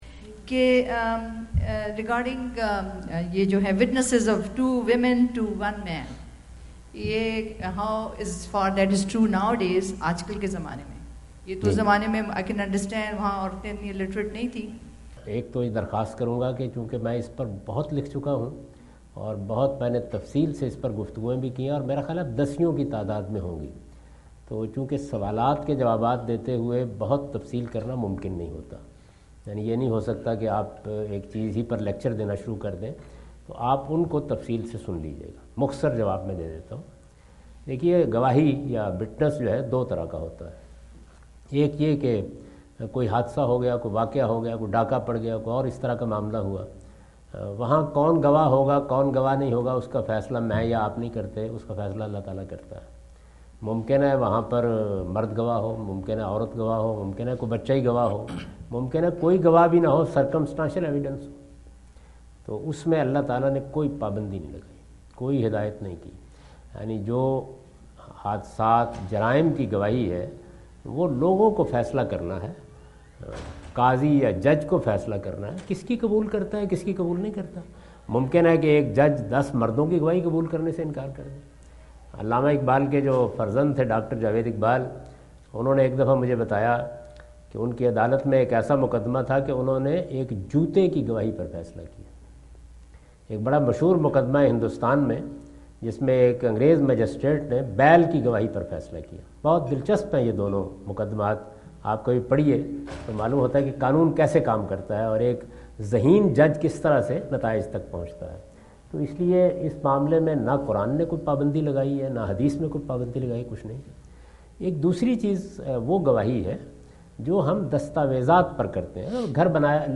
Category: Foreign Tours / USA 2017 / Questions_Answers /
Javed Ahmad Ghamidi answer the question about "Testimony of Men and Women" During his US visit in Dallas on October 08,2017.